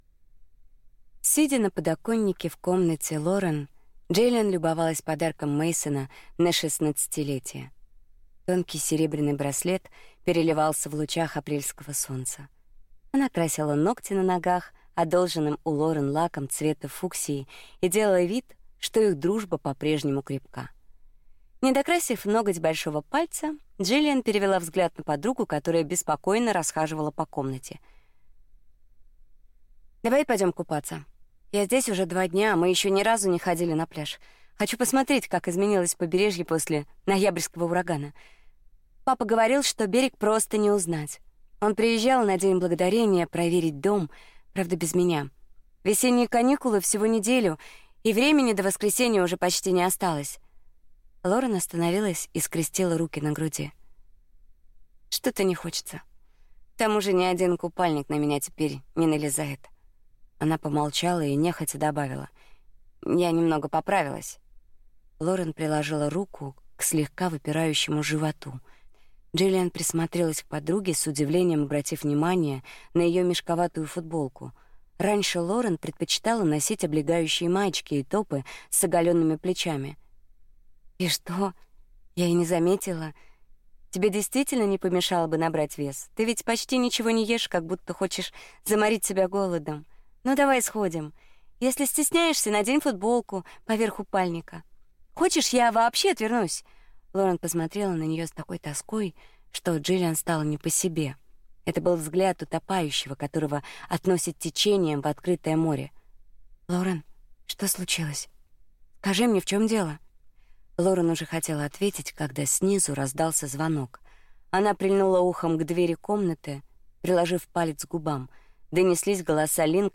Аудиокнига Колыбельная звезд | Библиотека аудиокниг